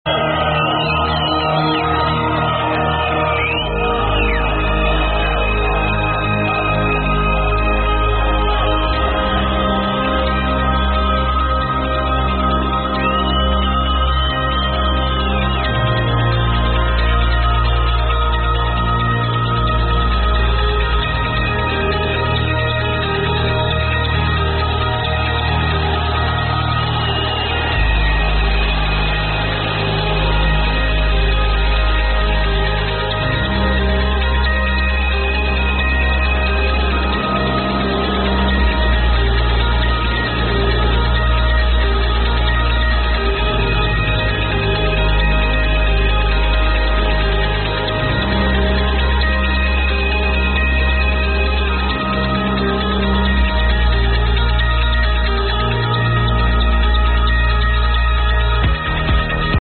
trancey track with guitar riff